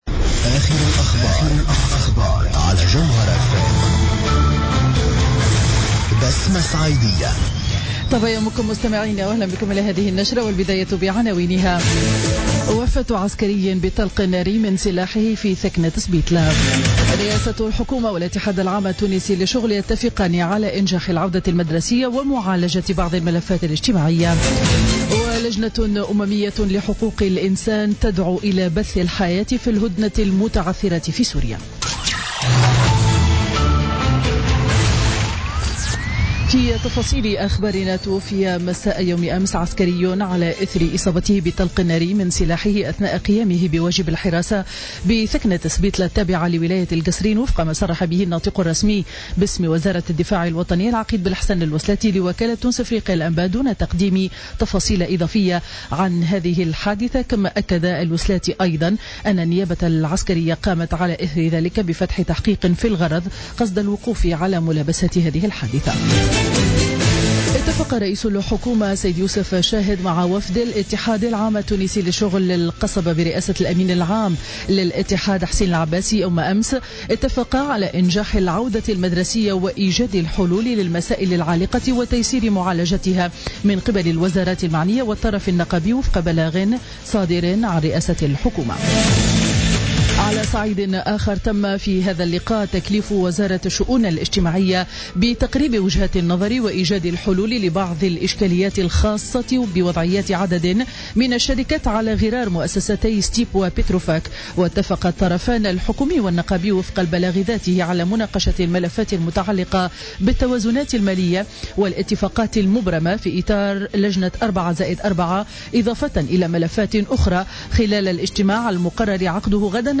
نشرة أخبار السابعة صباحا ليوم الاربعاء 7 سبتمبر 2016